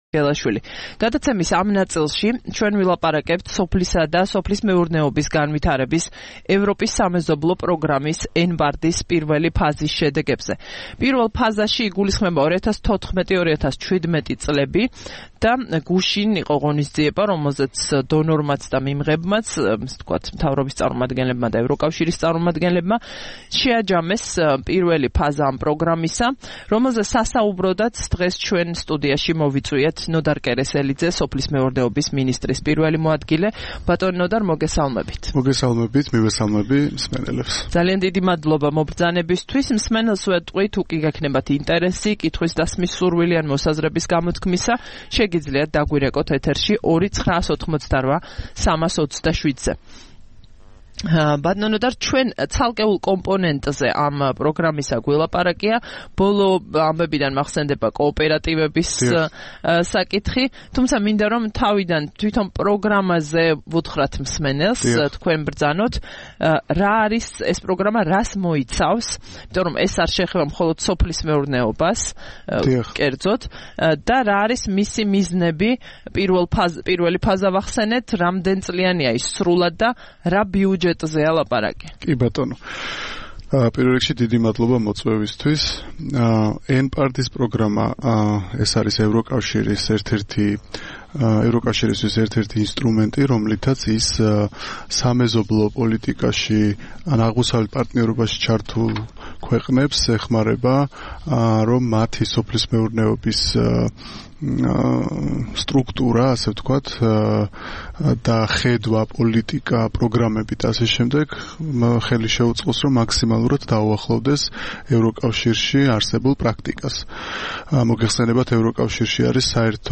14 დეკემბერს რადიო თავისუფლების "დილის საუბრების" სტუმარი იყო ნოდარ კერესელიძე, სოფლის მეურნეობის მინისტრის პირველი მოადგილე.